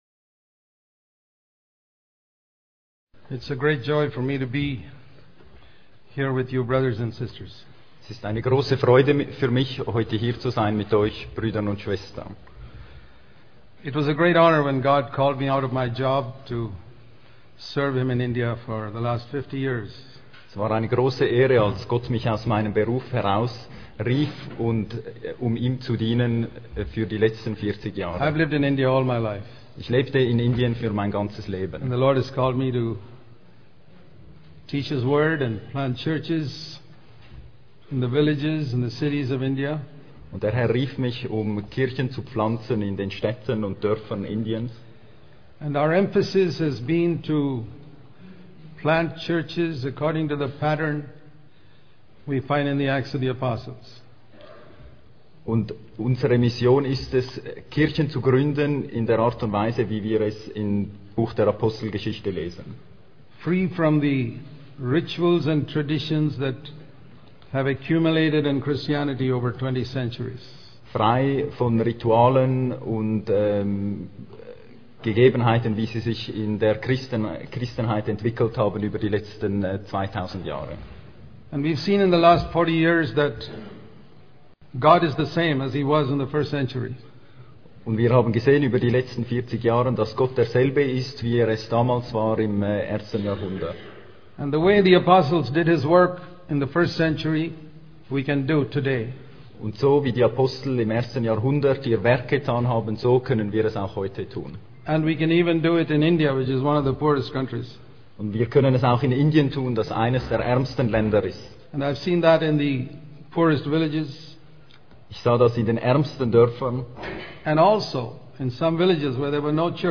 speaking at the Kwa Sizabantu European pastors conference in Bilten in Switzerland in April 2016